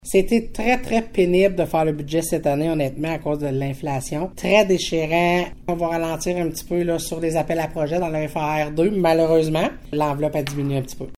L’exercice n’aura pas été simple à réaliser, comme le mentionne la préfète Lamarche :